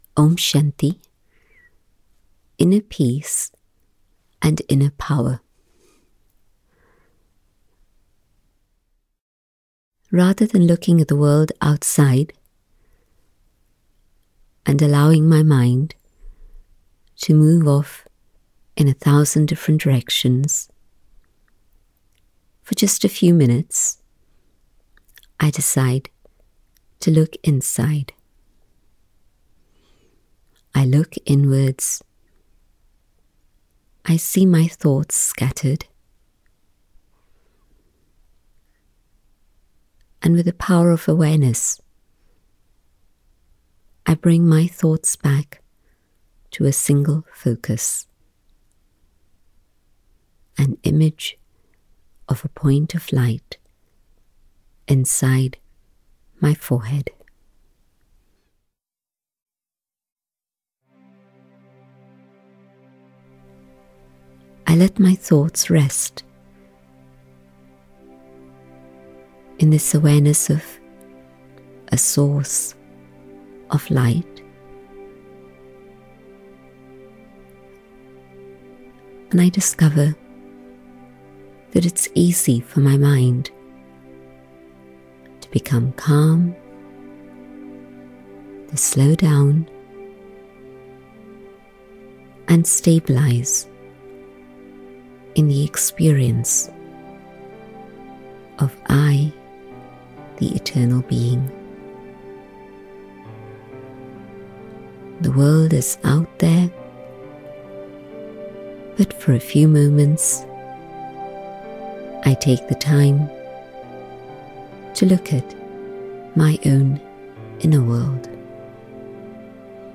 Downloadable Meditations